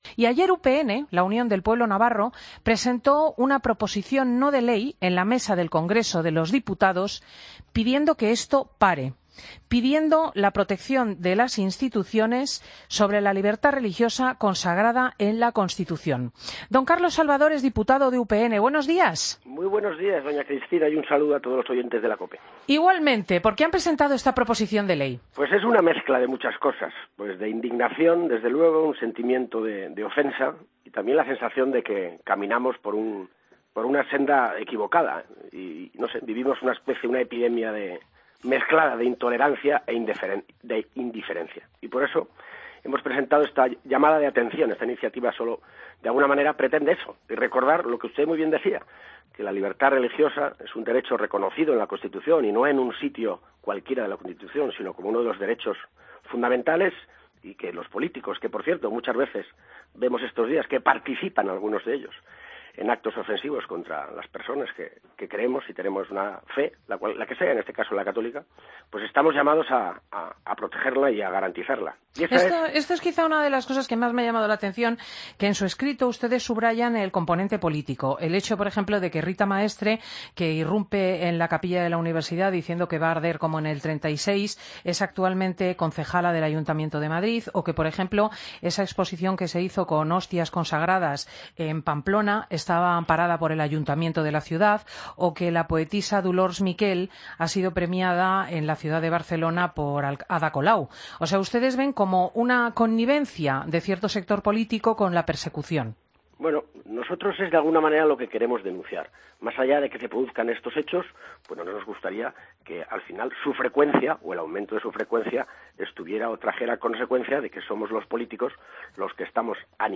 Escucha le entrevista a Carlos Salvador, Diputado en UPN, en Fin de Semana COPE.